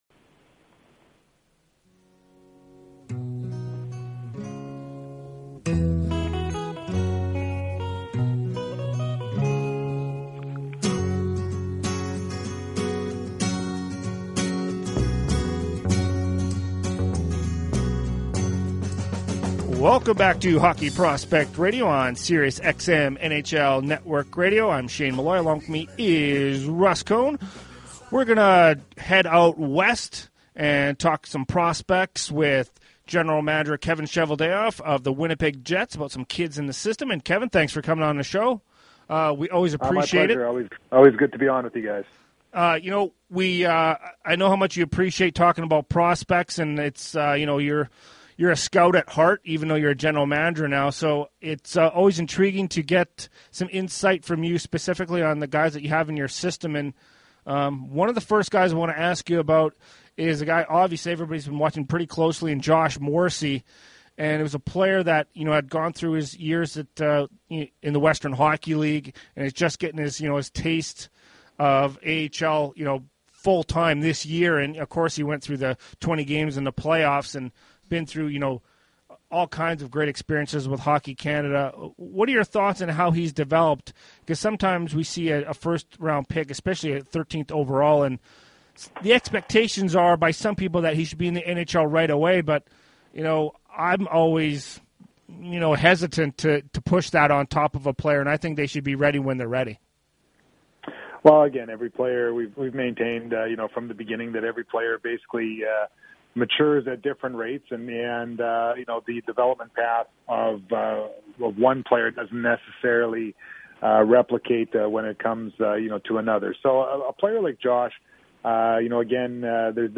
Winnipeg Jets General Manager Kevin Cheveldayoff was a guest on Sirius NHL Radio’s Hockey Prospects show